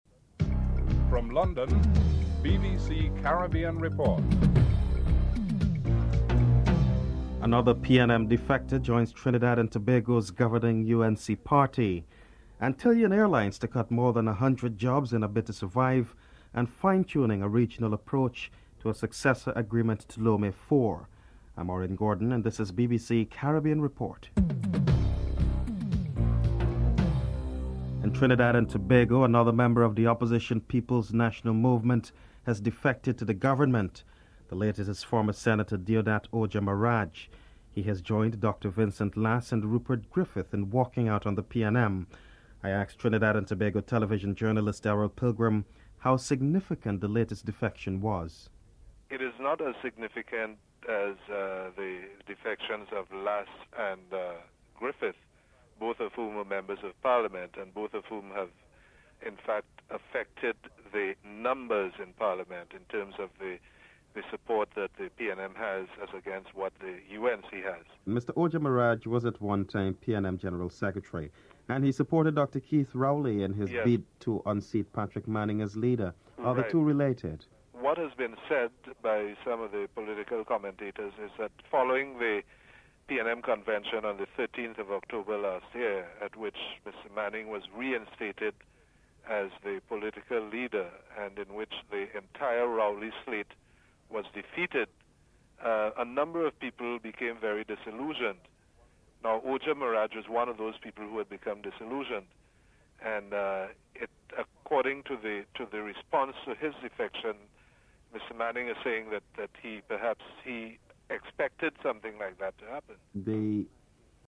1. Headlines (00:00-00:28)
7. A papal visit to Cuba next year may enhance the role of the church there. Cardinal Bernard Law of Boston is interviewed (12:22-14:06